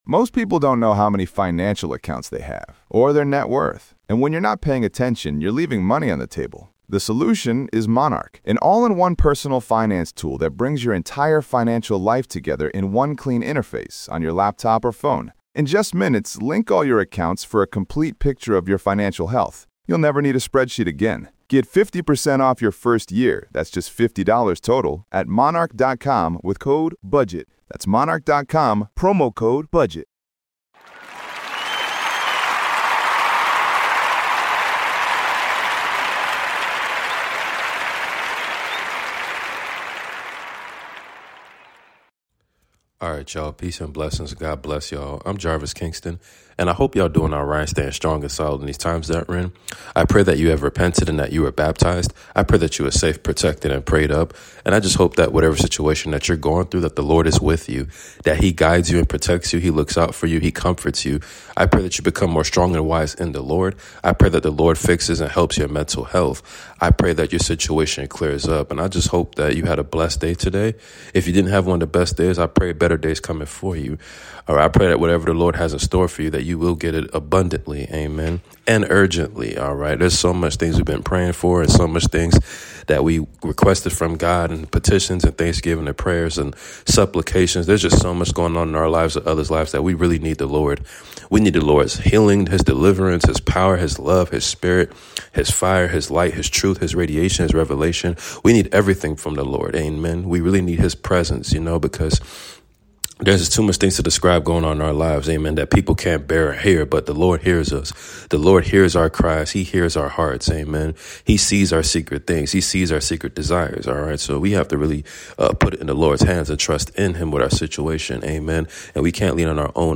Prayers up family! 1 Samuel reading !